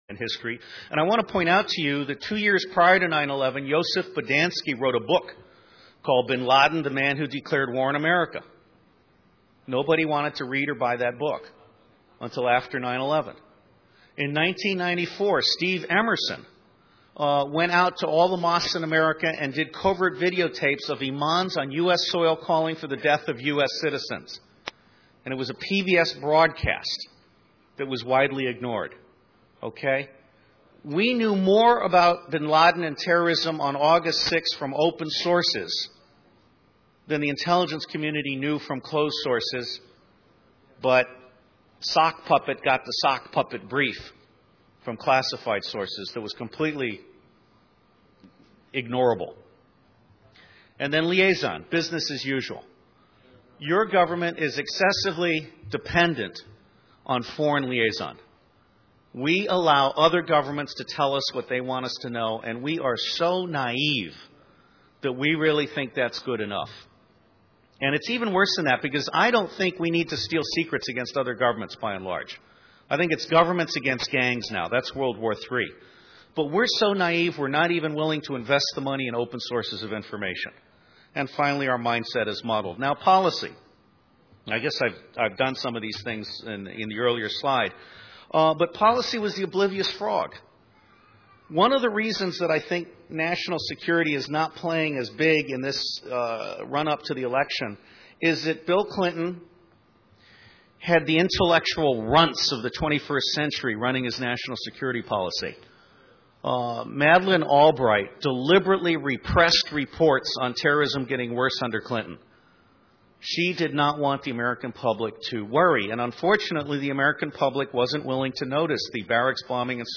a speech